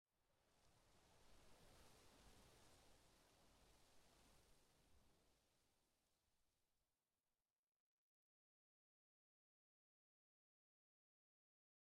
wind7.ogg